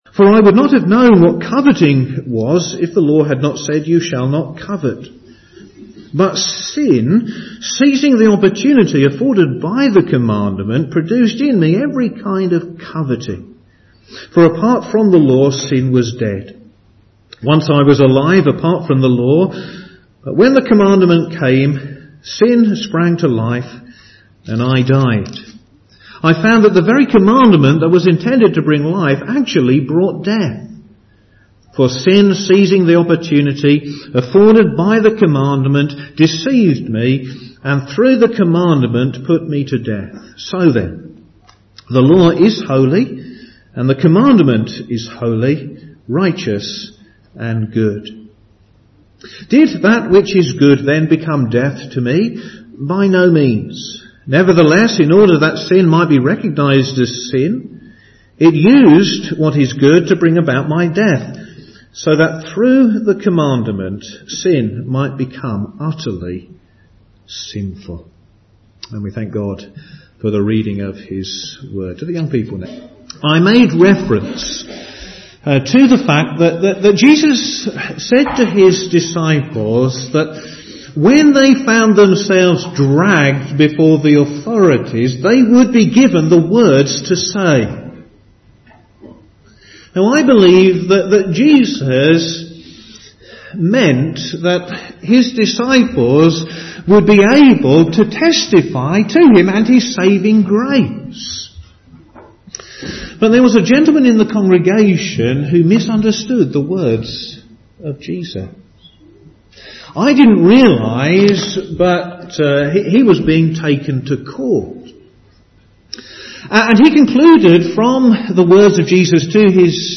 All sermons preached at Crockenhill Baptist Church
Service Morning